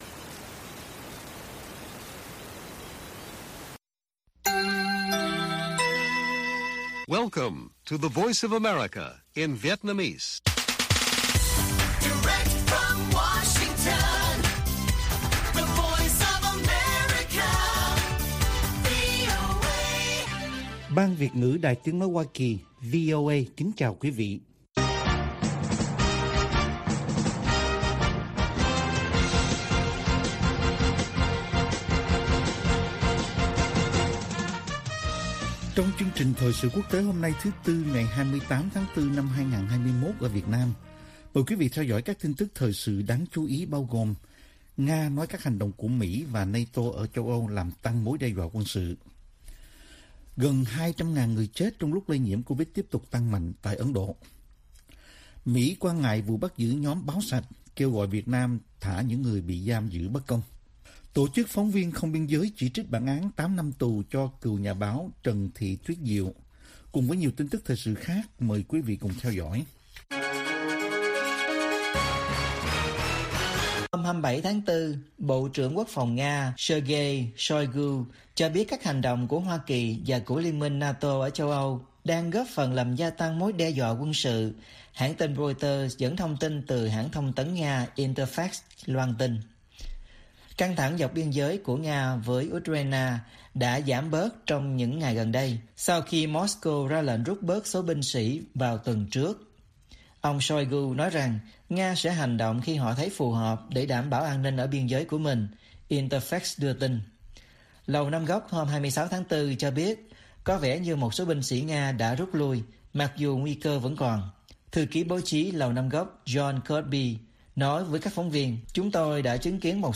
Bản tin VOA ngày 28/4/2021